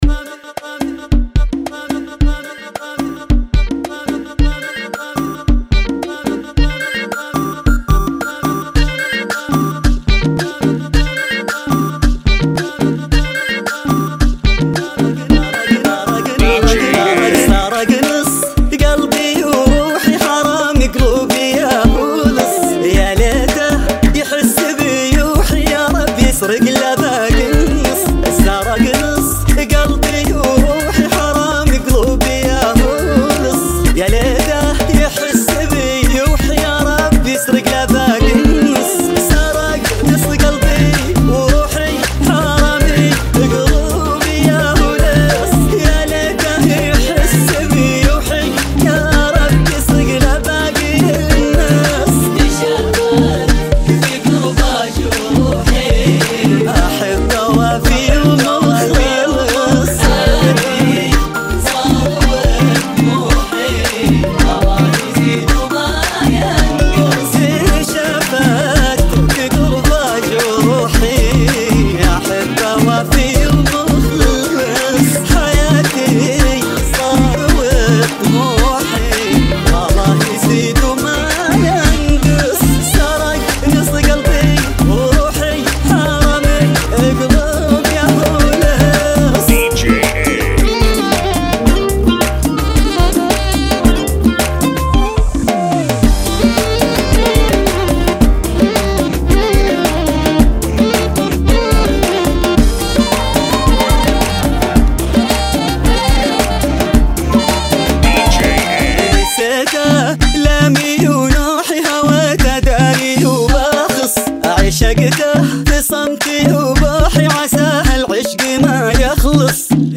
Funky [ 110 Bpm